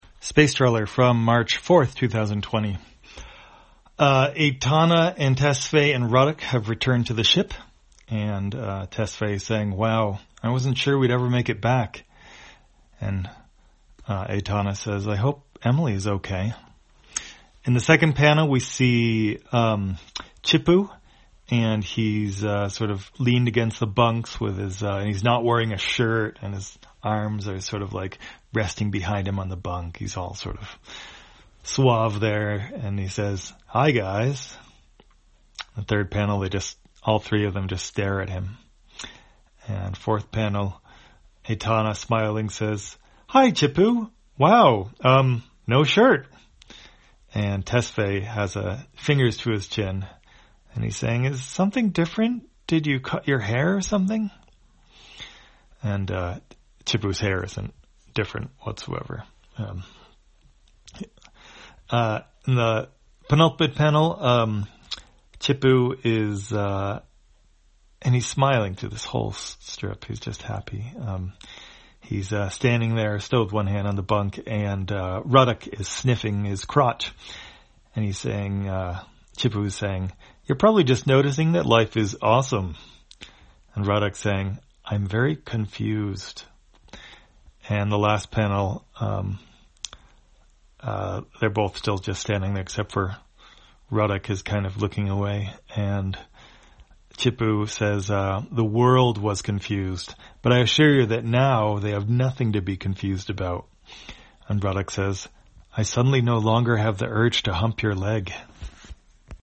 Spacetrawler, audio version For the blind or visually impaired, March 4, 2020.